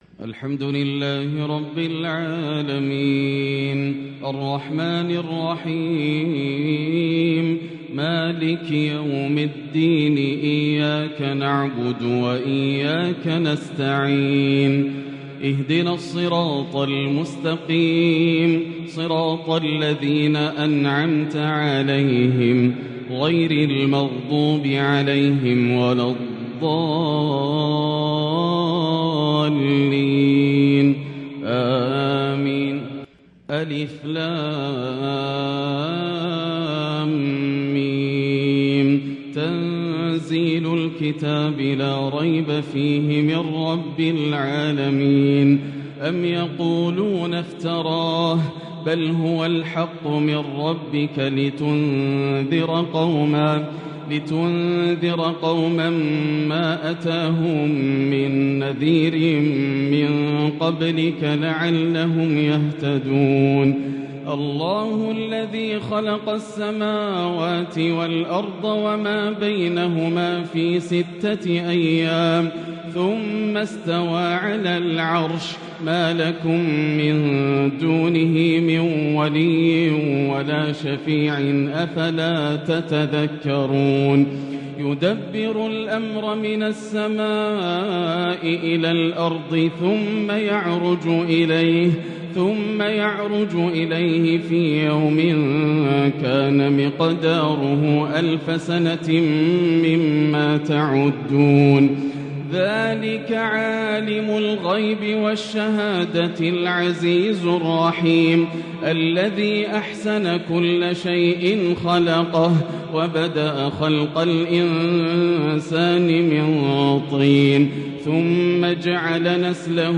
بانفراد تام و أداء متجدد الشيخ ياسر الدوسري يأسر الأسماع | سورتي السجدة و الإنسان ومن بدايات طه > تلاوات عام 1443هـ > مزامير الفرقان > المزيد - تلاوات الحرمين